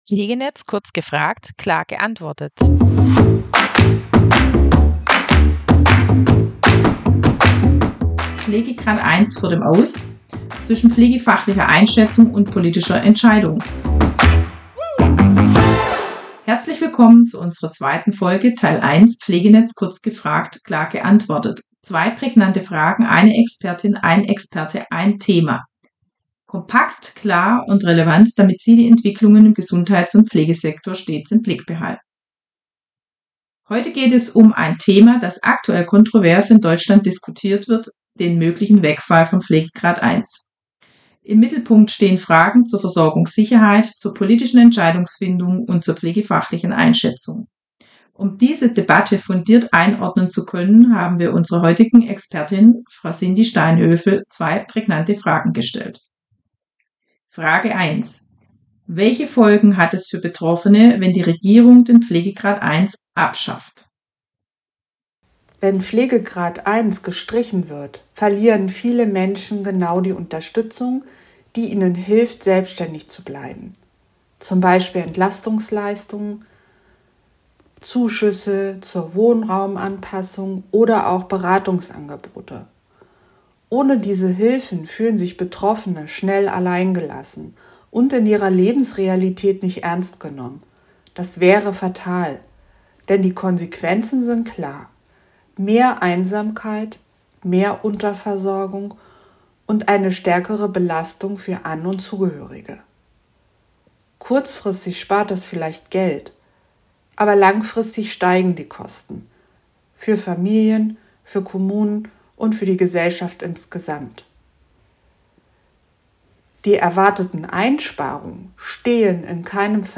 Zwei pointierte Fragen, eine Expertin oder ein Experte, ein Thema – kompakt, klar und relevant.